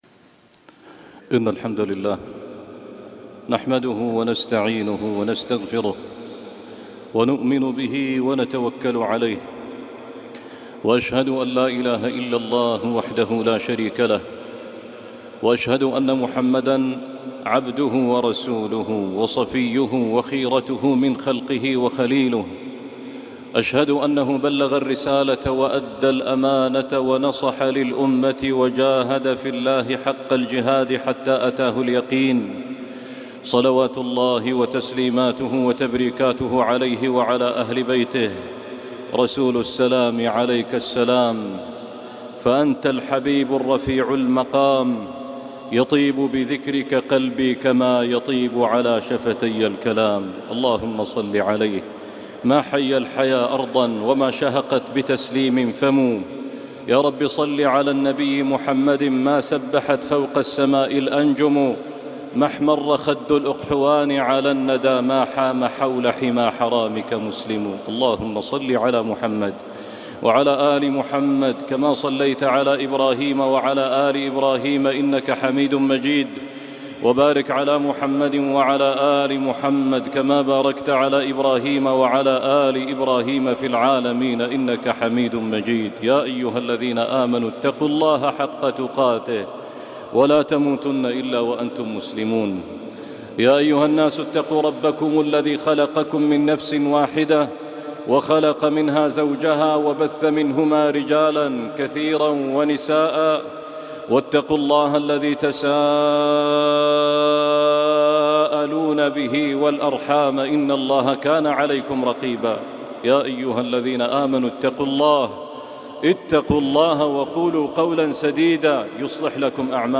دقات قلب - خطبة وصلاة الجمعة